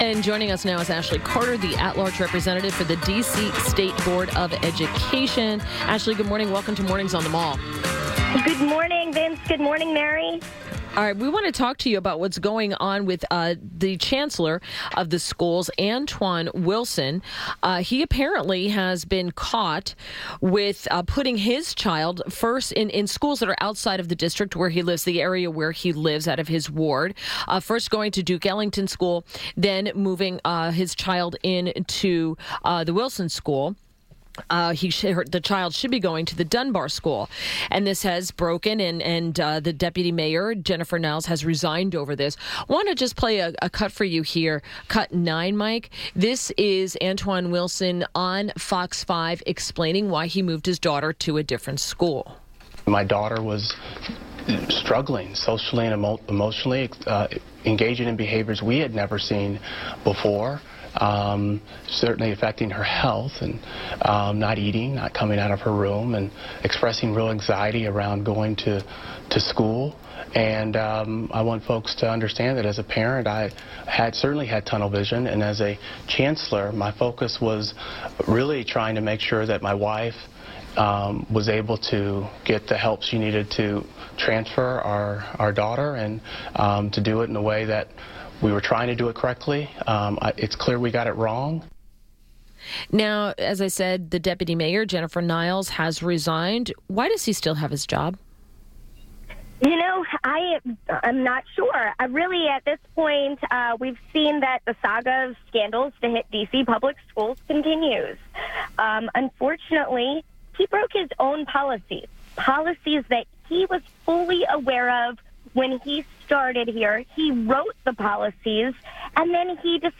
WMAL Interview - ASHLEY CARTER - 02.20.18